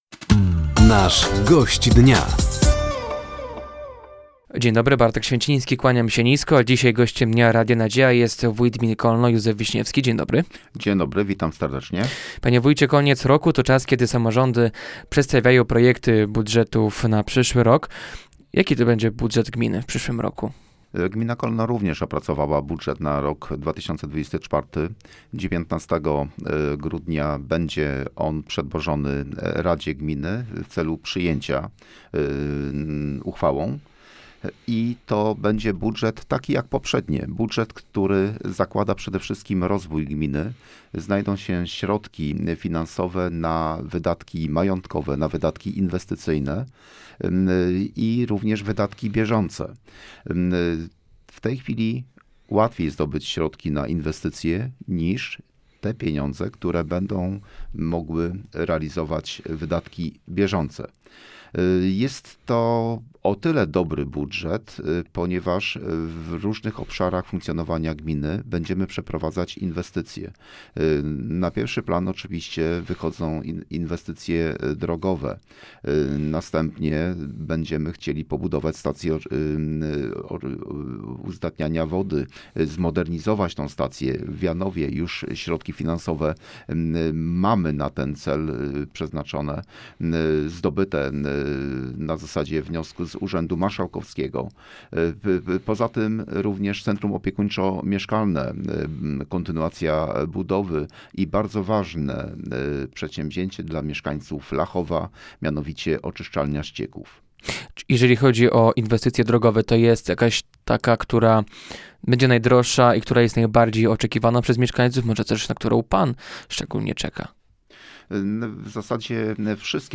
Gościem Dnia Radia Nadzieja był wójt gminy Kolno, Józef Wiśniewski. Tematem rozmowy był budżet gminy na przyszły rok. Wójt podsumował również kończący się rok.